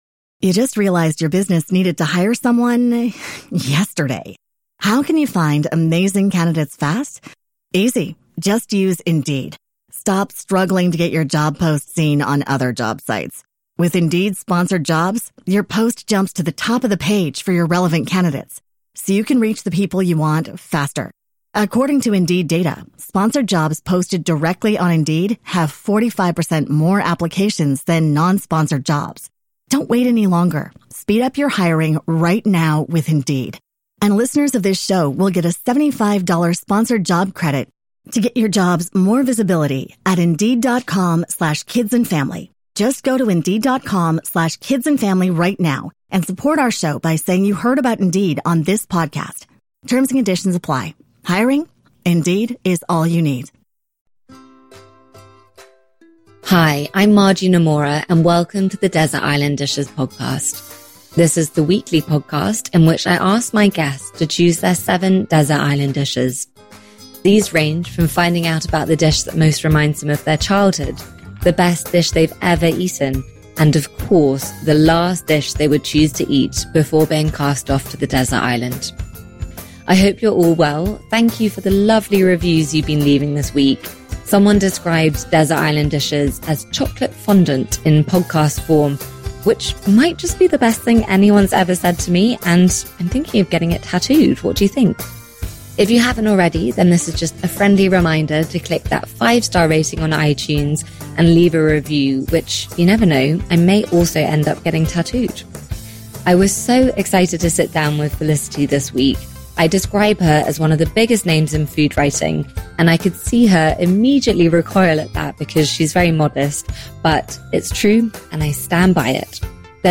My guest today is Felicity Cloake.